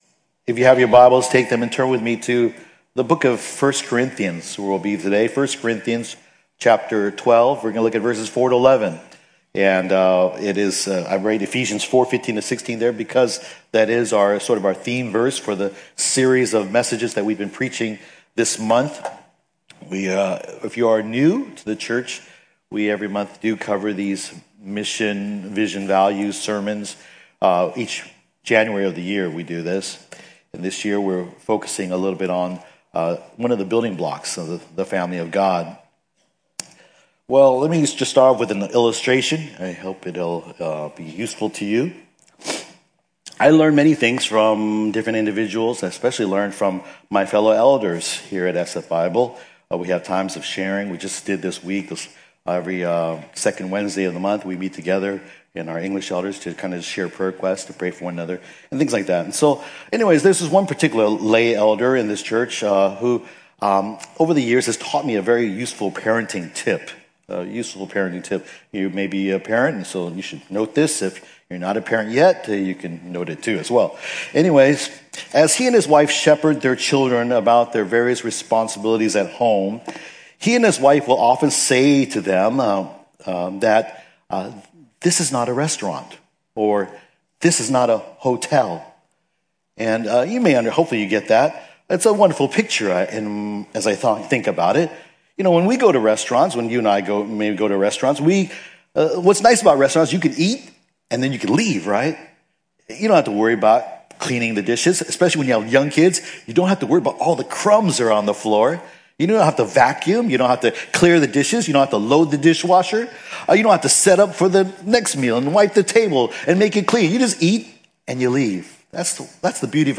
Subscribe to listen to San Francisco Bible Church's weekly Sunday morning worship service as we walk through verse by verse throughout the Bible in hopes that your heart will walk with Jesus faithfully, serve Him diligently, and proclaim Him boldly.
San Francisco Bible Church - Sunday Sermons